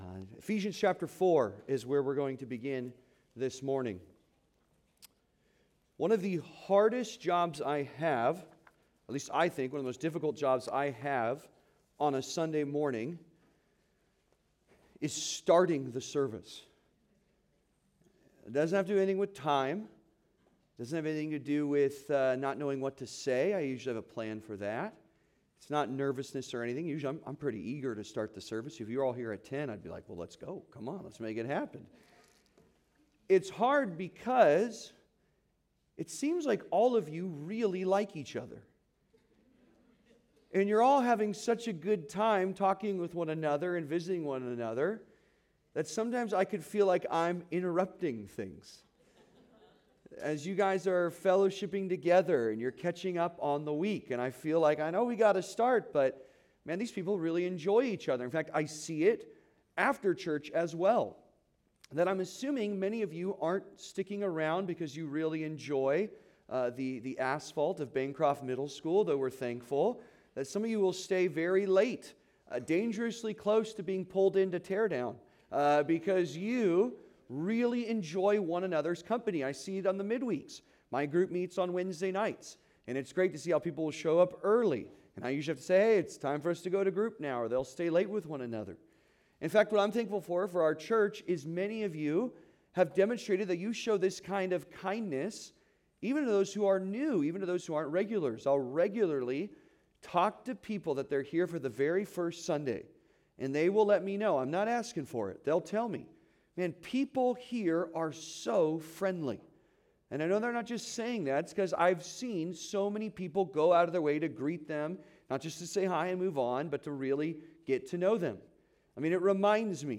The Marvel of a United Church (Sermon) - Compass Bible Church Long Beach